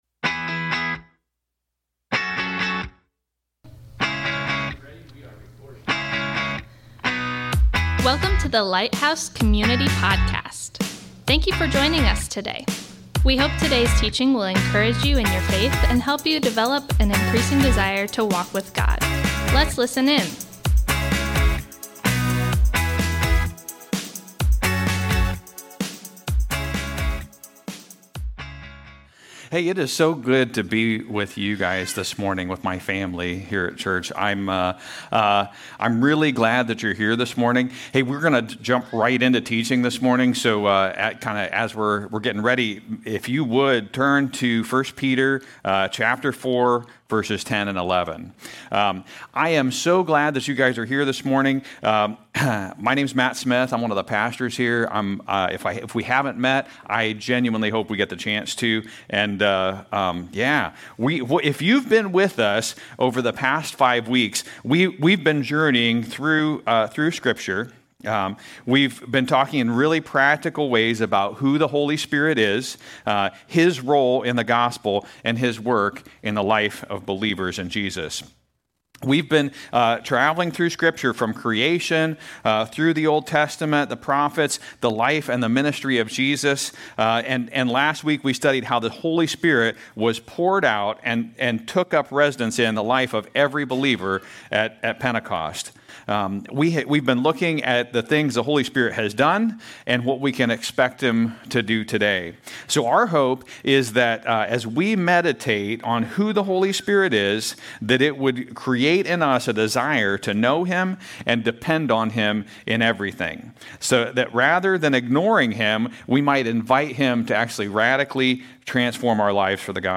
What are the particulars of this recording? Thanks for joining us today as we worship together.